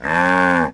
moo1.ogg